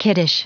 Prononciation du mot kiddish en anglais (fichier audio)
kiddish.wav